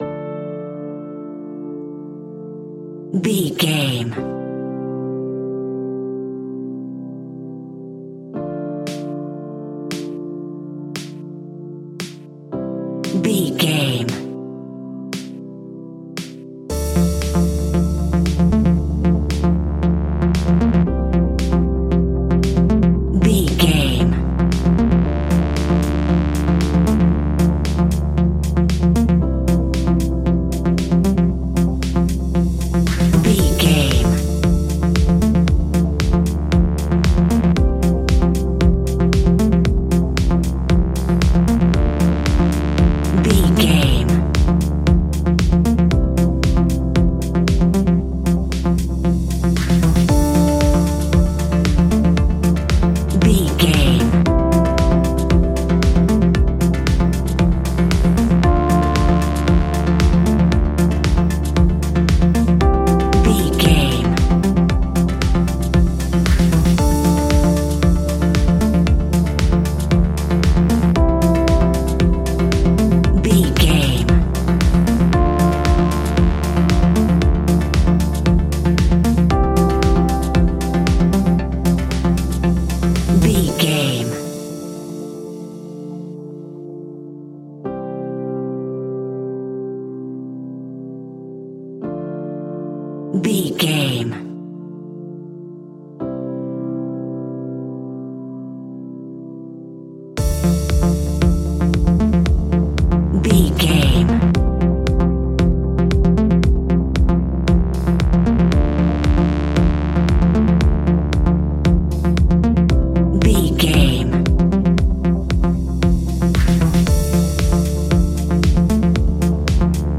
Aeolian/Minor
groovy
uplifting
futuristic
driving
energetic
repetitive
synthesiser
drum machine
electric piano
dance
synth leads
synth bass